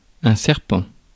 Ääntäminen
Synonyymit couleuvre Ääntäminen France: IPA: [sɛʁ.pɑ̃] Tuntematon aksentti: IPA: /sɛʁp/ Haettu sana löytyi näillä lähdekielillä: ranska Käännös Substantiivit 1. змия {f} Muut/tuntemattomat 2. змия́ {f} Suku: m .